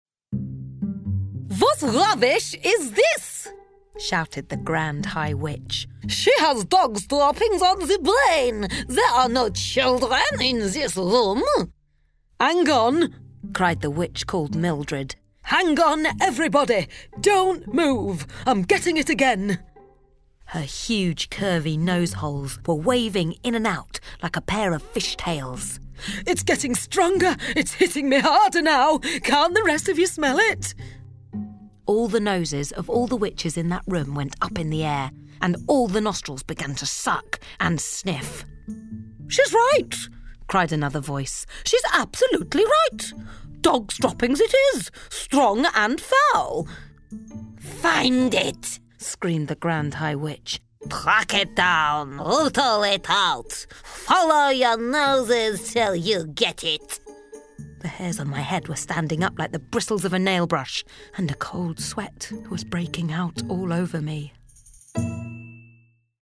• Native Accent: London, Neutral, RP
• Home Studio